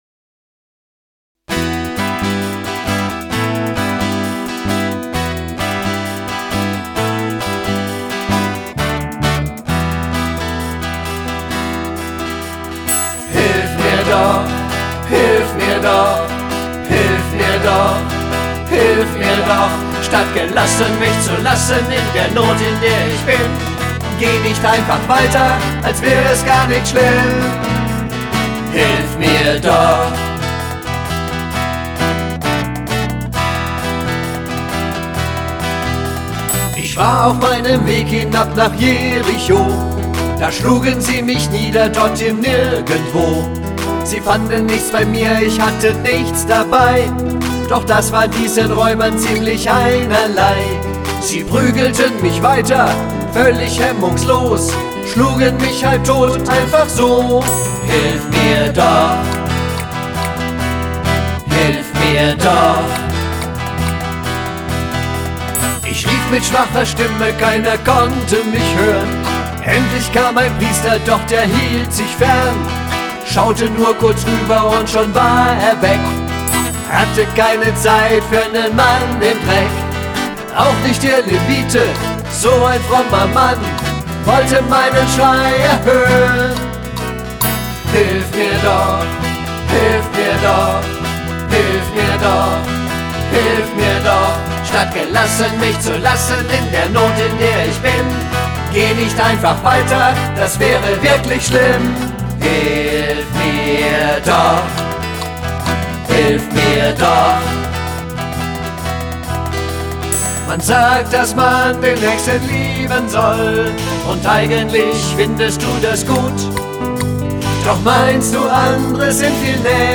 Das Gleichnis vom barmherzigen Samaritaner – rockig vertont